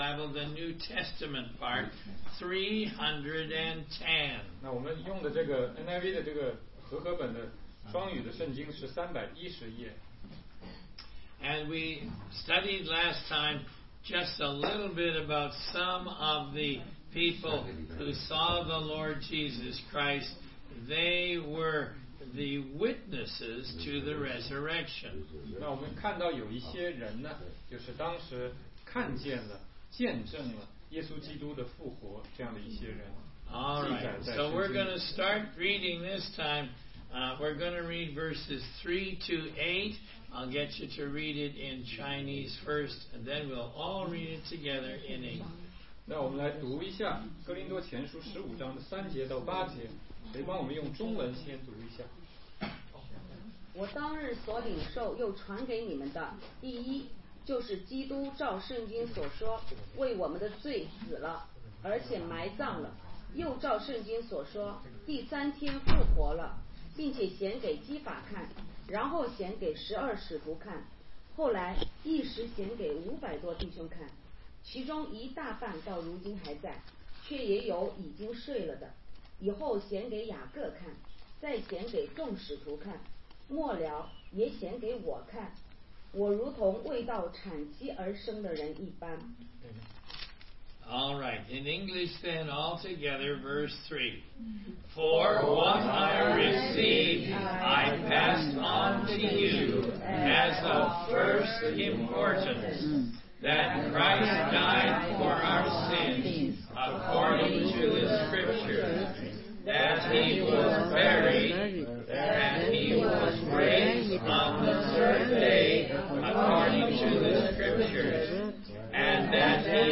16街讲道录音 - 主耶稣现在在做什么